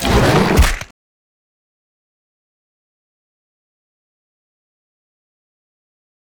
vpunch1.ogg